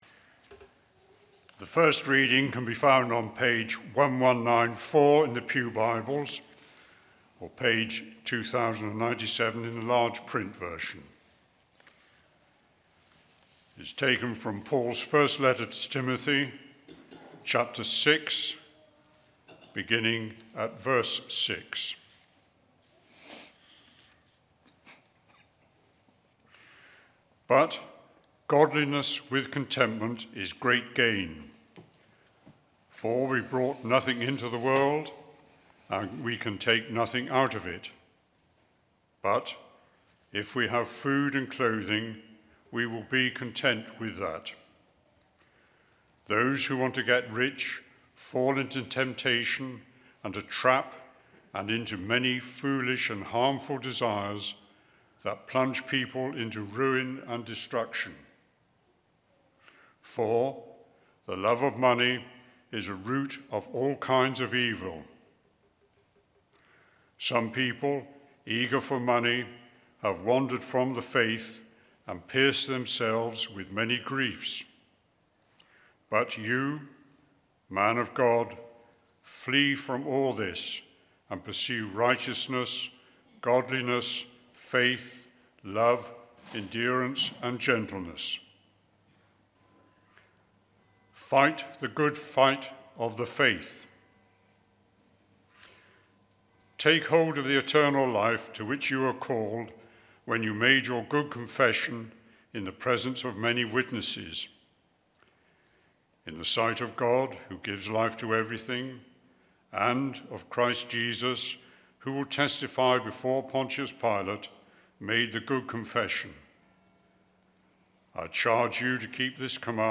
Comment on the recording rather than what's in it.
Holy Communion Mourning Service for Her Majesty Sermon